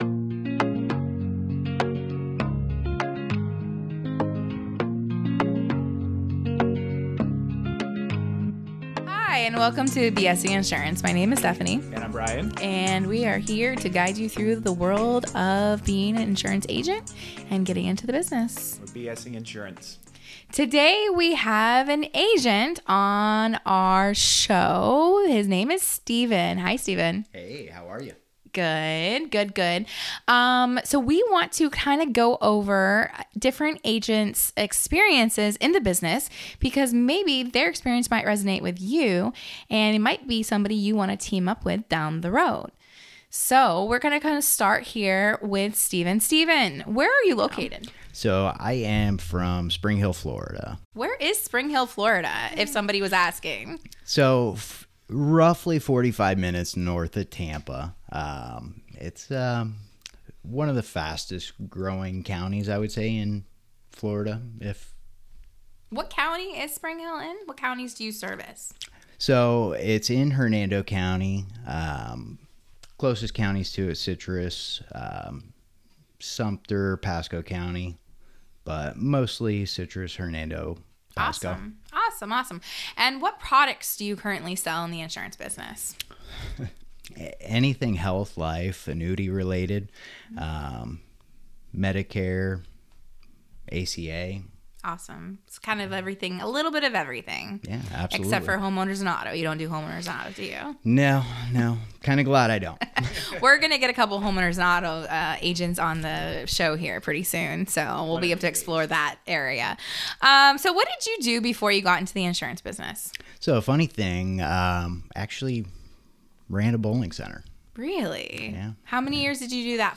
Agent Interview